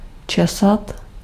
Ääntäminen
Synonyymit uitkammen kemmen Ääntäminen Tuntematon aksentti: IPA: /kɑ.mə(n)/ Haettu sana löytyi näillä lähdekielillä: hollanti Käännös Ääninäyte 1. česat Luokat Germaanisista kantakielistä johdetut sanat Karvoitus Verbit